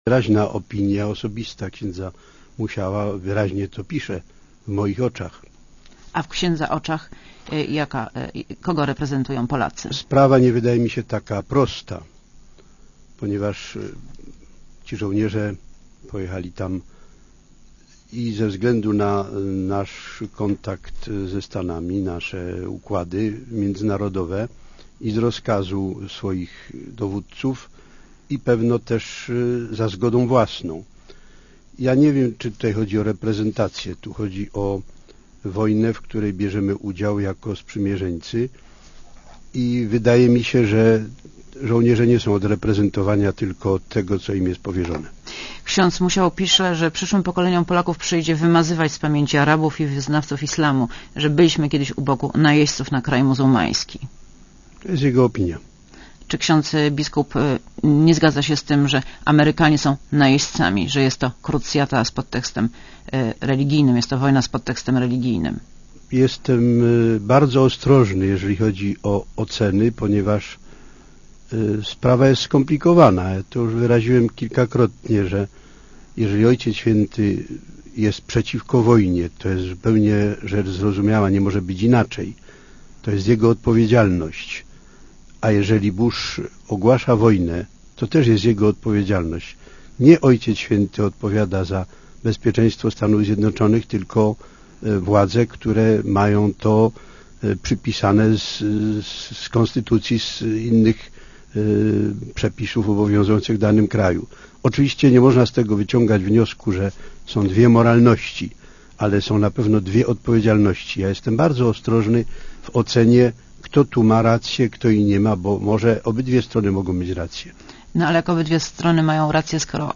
Monika Olejnik rozmawia z biskupem Tadeuszem Pieronkiem